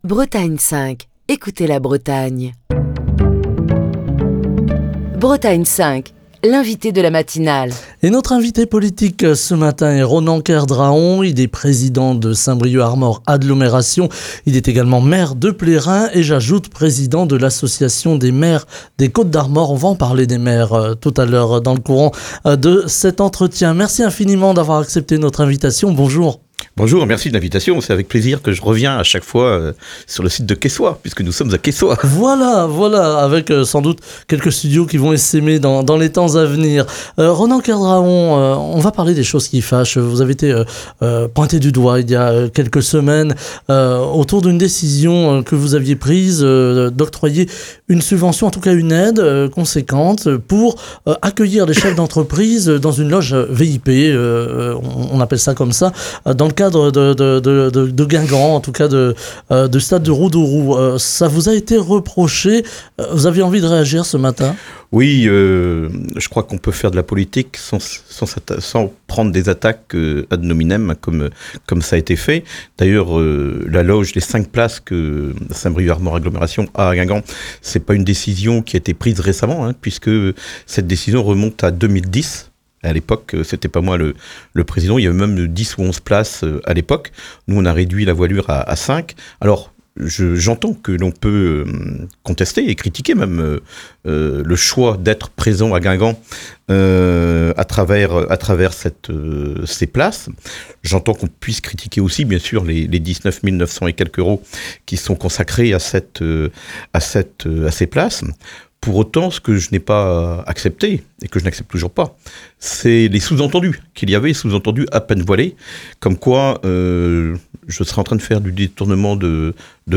Le gouvernement demande 5 milliards d'économies aux collectivités qui craignent de ne plus pouvoir assumer l'intégralité de leurs missions. On en parle ce jeudi avec Ronan Kerdraon, président de Saint-Brieuc Armor Agglomération, maire de Plérin, président de l'association des maires des Côtes d'Armor qui est l'invité de la matinale de Bretagne 5.